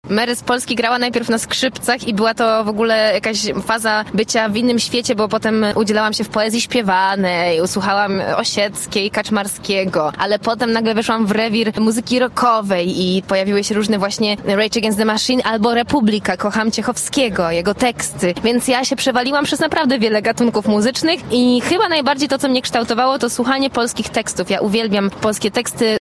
W studiu RZG opowiadała o swojej twórczości.